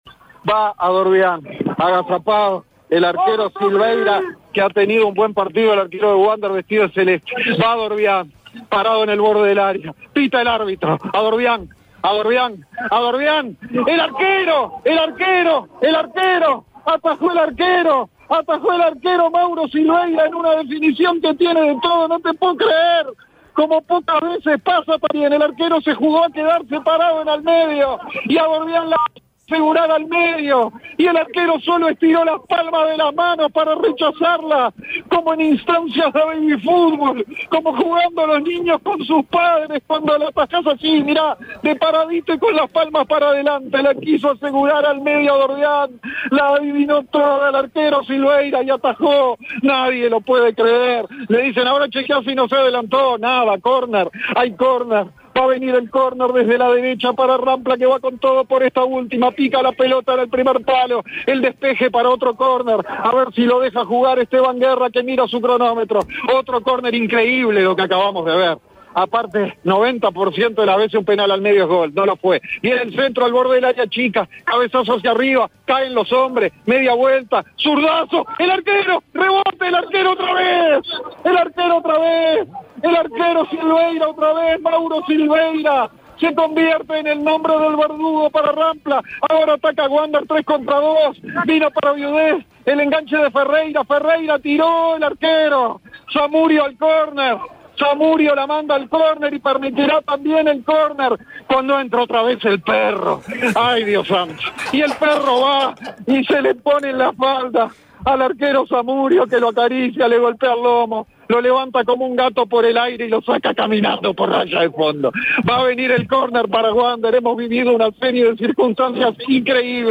El relato del final de la definición infartante y polémica entre picapiedras y bohemios que definió la permanencia y el descenso